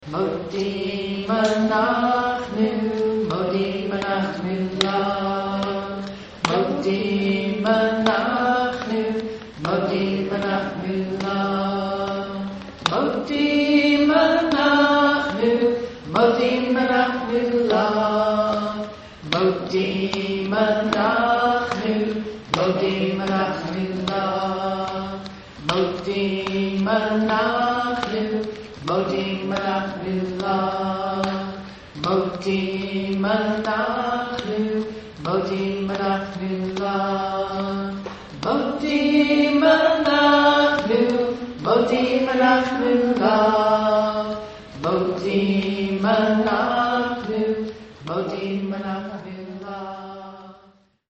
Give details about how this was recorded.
For more than 15 years we met monthly in the Reutlinger Community Synagogue.